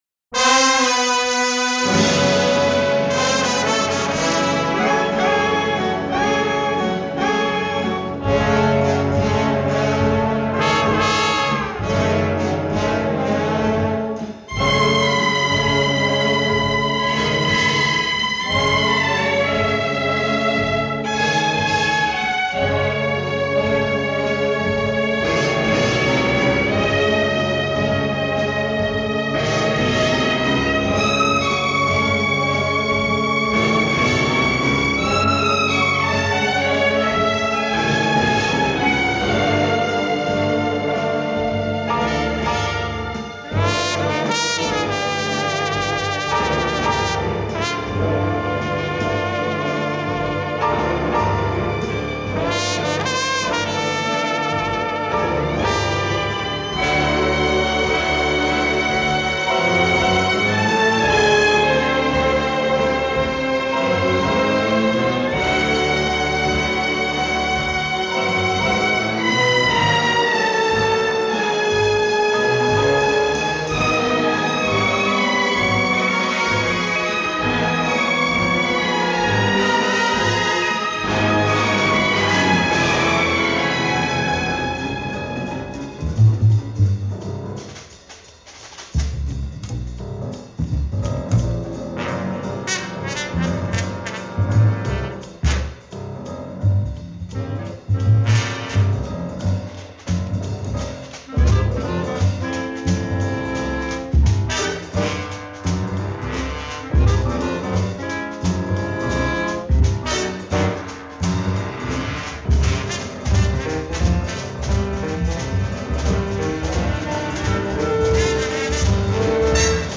Soundtrack, Classical